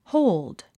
発音
hóuld　ホーゥド